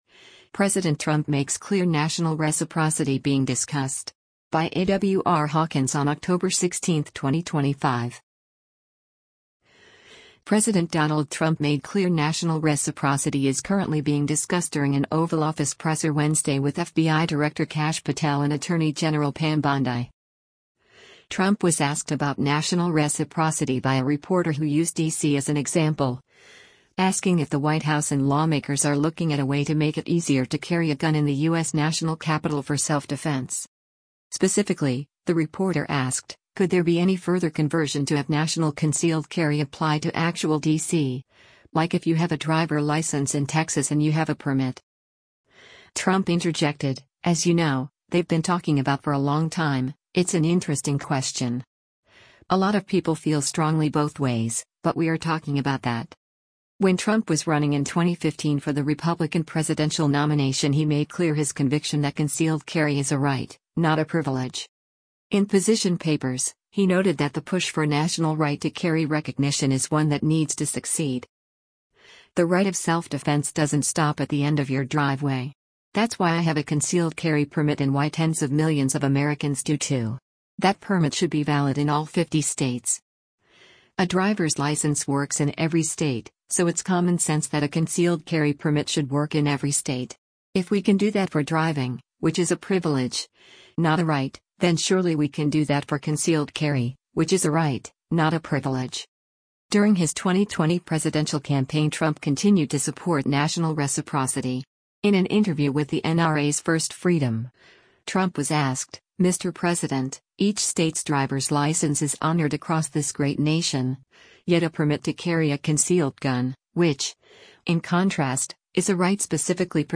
President Donald Trump made clear national reciprocity is currently being discussed during an Oval Office presser Wednesday with FBI director Kash Patel and Attorney General Pam Bondi.
Trump was asked about national reciprocity by a reporter who used D.C. as an example, asking if the White House and lawmakers are looking at a way to make it easier to carry a gun in the U.S. National Capital for self-defense.